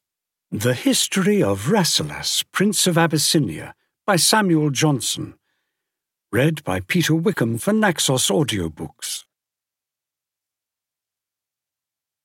The History of Rasselas (EN) audiokniha
Ukázka z knihy